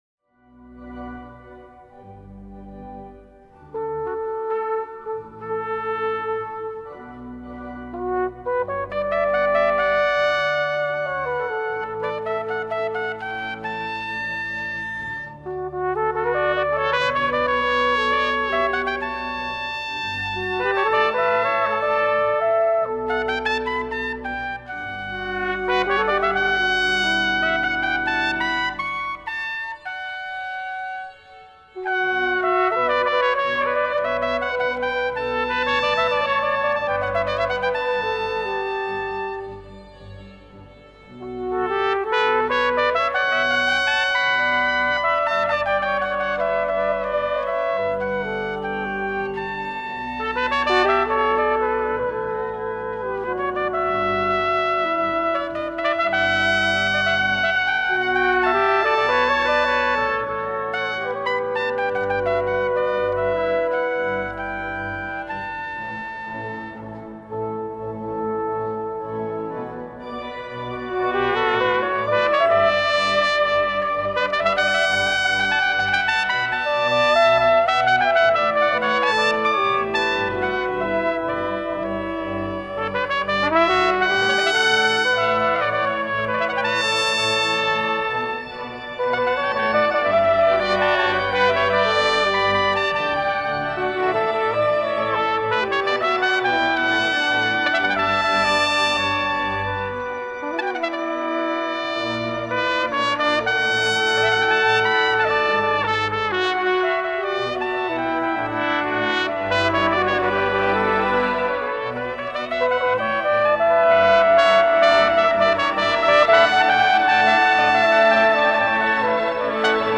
Orgel: Vermeulen Orgel Koepelkerk Leeuwarden
Op trompet, piccolotrompet, bugel en french horn
- 2.Bugel trompet en piccolotrompet